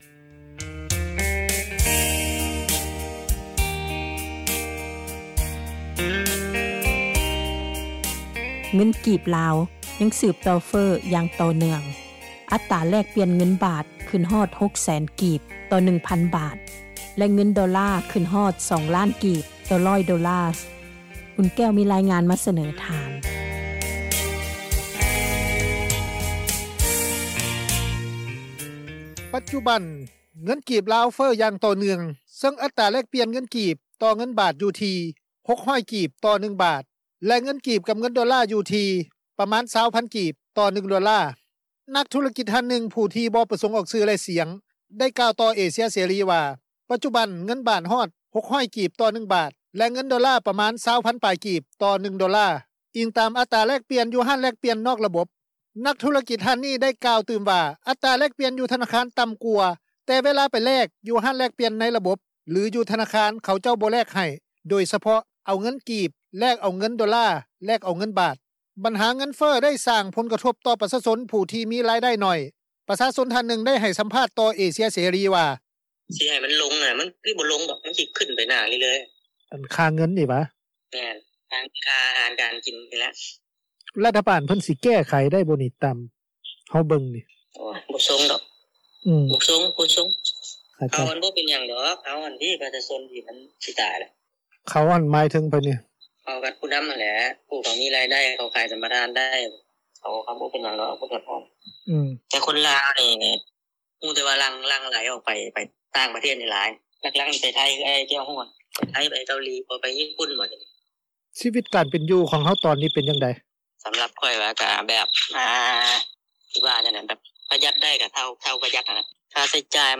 ປະຊາຊົນ ທ່ານນຶ່ງ ໄດ້ໃຫ້ສໍາພາດຕໍ່ເອເຊັຽເສຣີ ວ່າ:
ເຈົ້າໜ້າທີ່ຜູ້ທີ່ເຮັດວຽກ ດ້ານພັທນາ ທ່ານນຶ່ງ ໄດ້ໃຫ້ສຳພາດຕໍ່ ວິທຍຸ ເອເຊັຽເສຣີ ວ່າ: